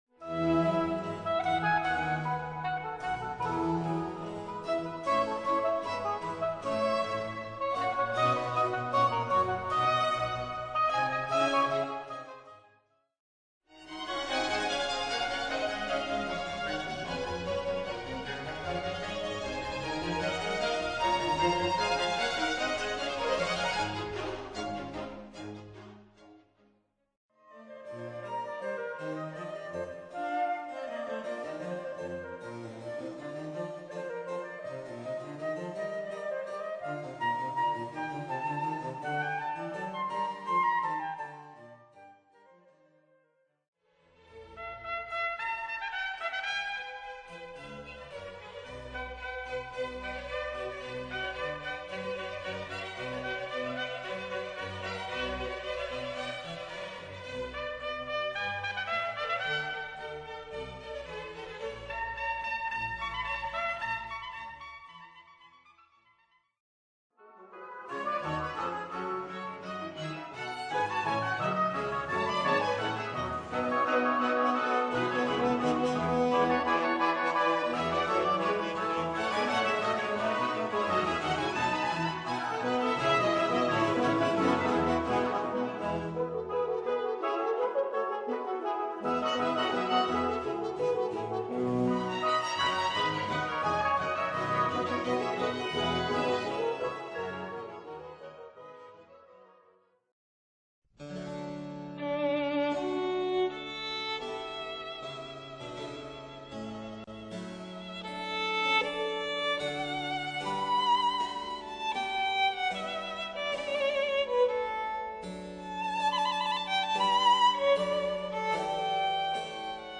Sonata, Concerto, Concerto Grosso sono tra le forme musicali più adottate nel Seicento.